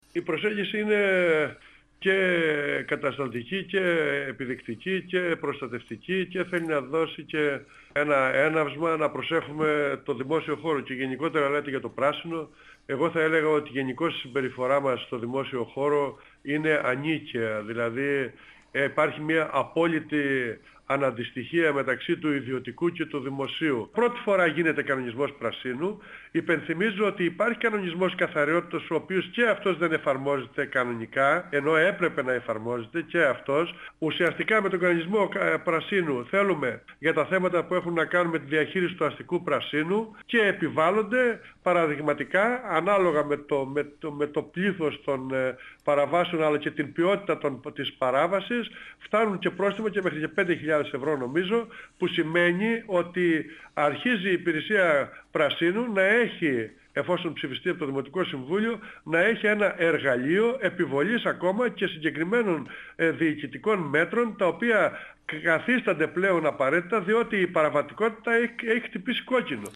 Ο αντιδήμαρχος Τεχνικών Εργων και Περιβάλλοντος, Θανάσης Παππάς, στον 102FM του Ρ.Σ.Μ. της ΕΡΤ3
Συνέντευξη